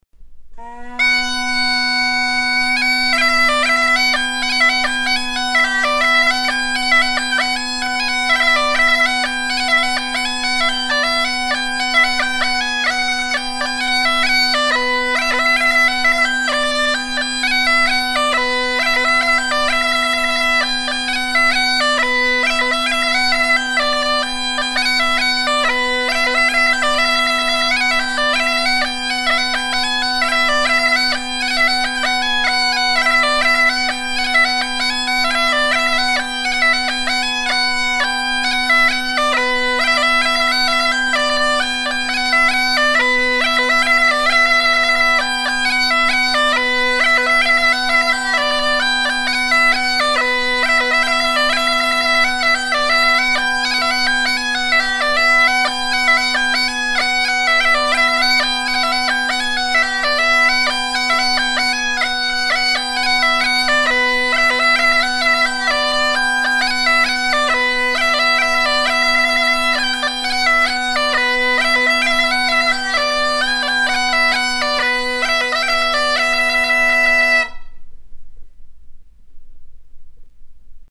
forme de rode ronde à trois pas pratiquée à l'île d'Yeu
danse : ronde : demi-rond
Pièce musicale éditée